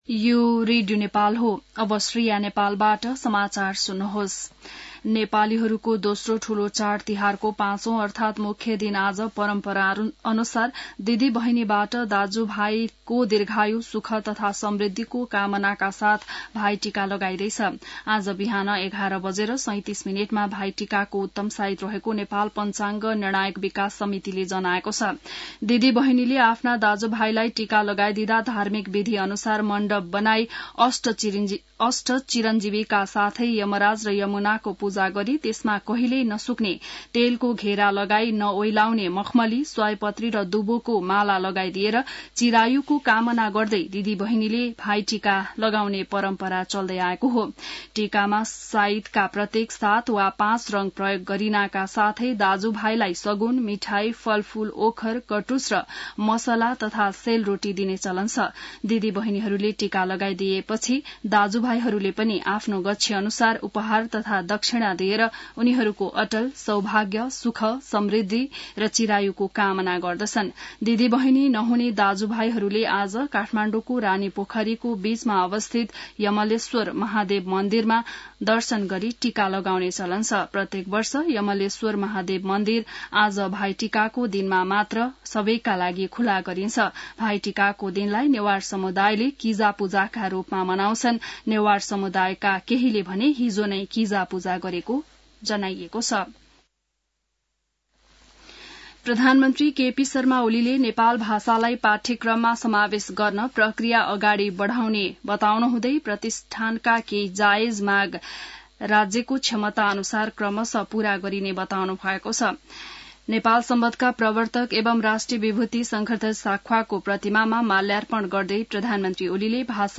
बिहान ६ बजेको नेपाली समाचार : १९ कार्तिक , २०८१